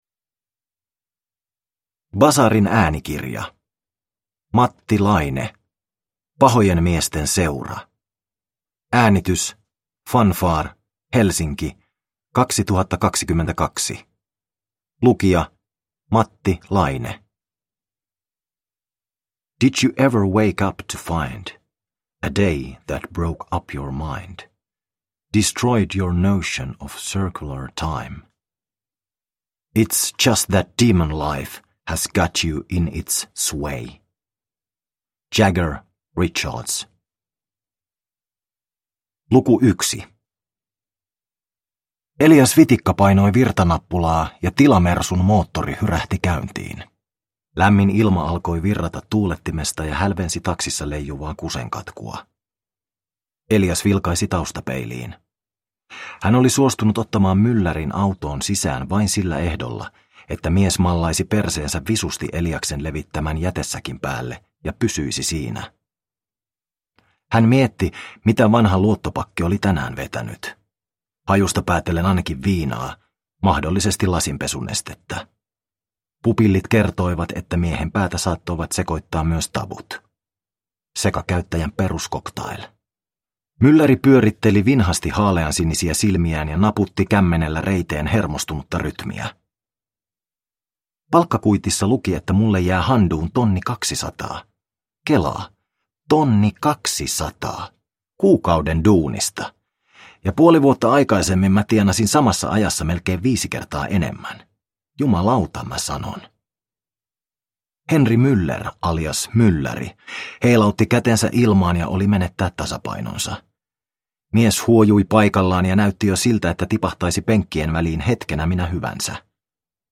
Pahojen miesten seura – Ljudbok – Laddas ner